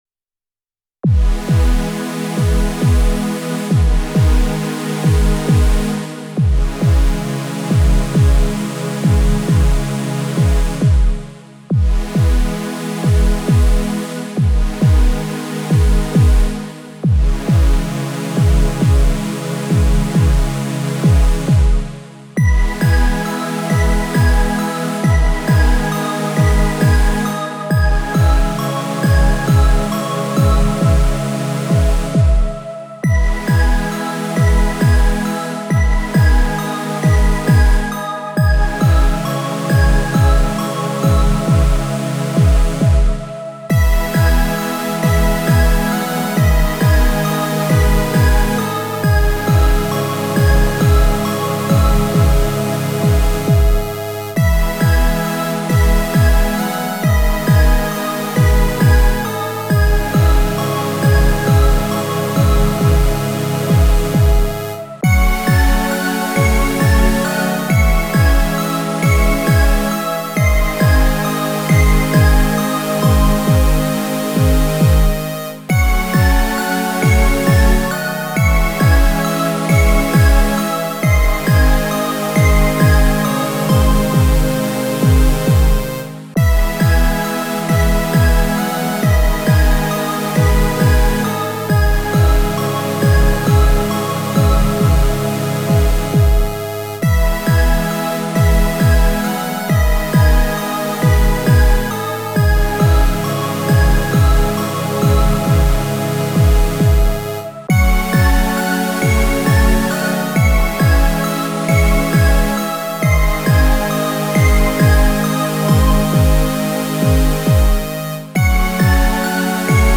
En La Caída yo quería que la música sonara como una cascada, pero con acordes trágicos, al igual que el clímax de la obra.